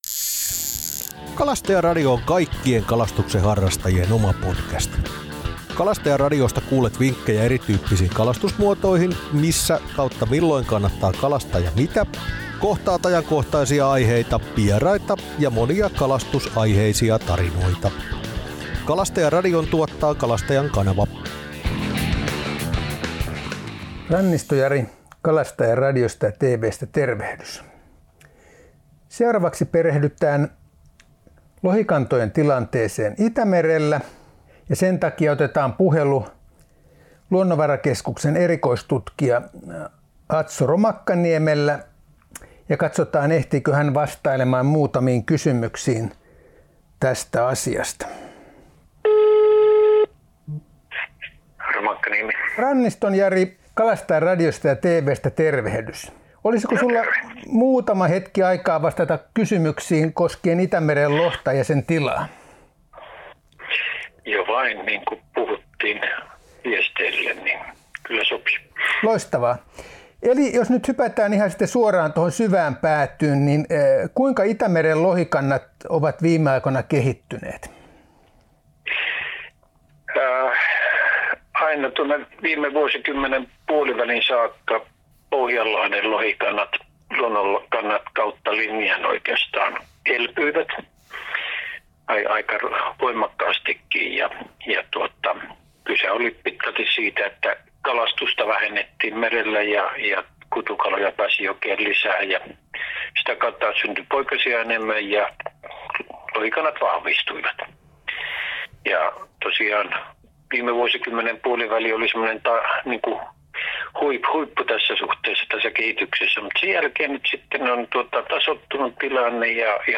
Kalastajan Radio Itämeren lohen tila - haastattelussa